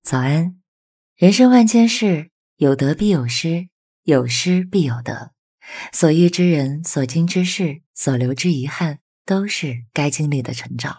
Inspiracyjny lektor do vlogów dla kinowego opowiadania historii
Przekształć swoje wizualne historie dzięki duchowemu, rezonującemu głosowi AI zaprojektowanemu, aby inspirować, motywować i łączyć się z odbiorcami na głębszym poziomie.
Kinowe tempo
Motywacyjny ton